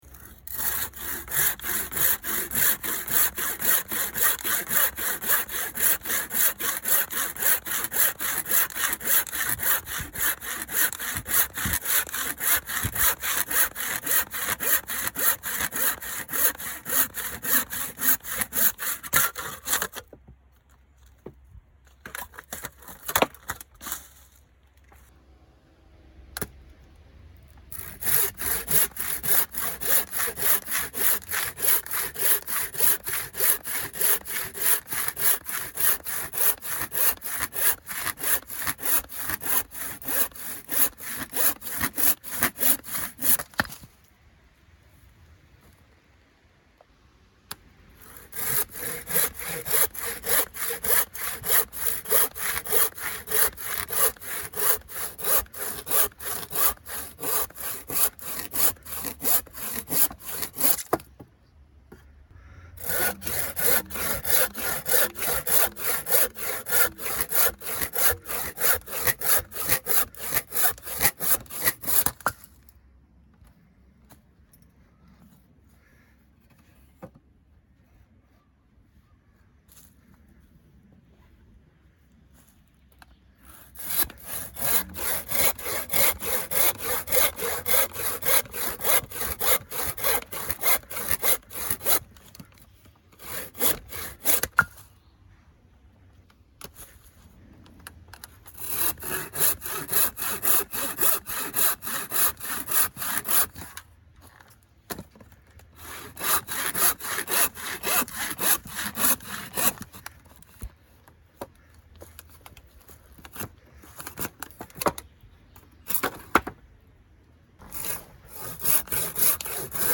Скачать звук ножовки по дереву и металлу
Звуки ручной пилы (ножовки) для распила дерева и металлических предметов слушать онлайн и скачать mp3 бесплатно.
Распиливание дерева и металла ножовкой в нескольких вариантах. Звуки в хорошем качестве, формат файлов mp3.